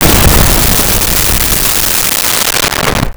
Explosion 02
Explosion 02.wav